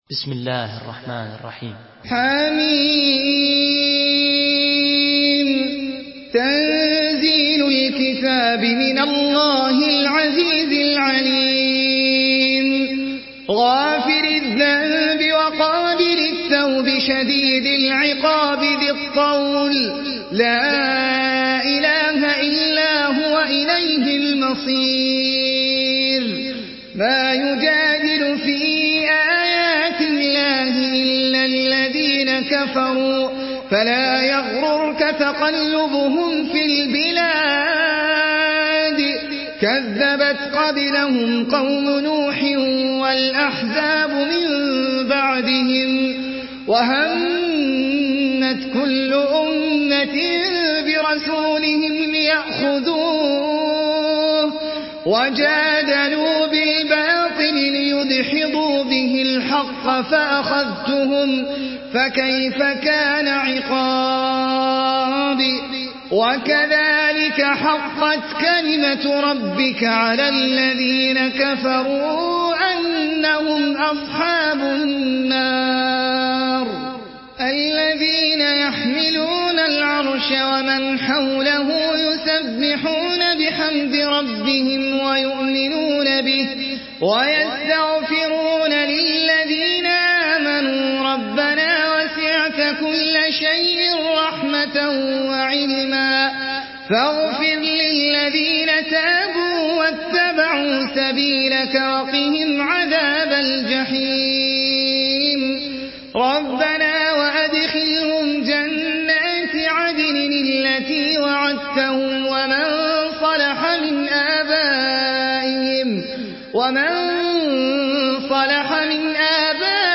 Une récitation touchante et belle des versets coraniques par la narration Hafs An Asim.
Murattal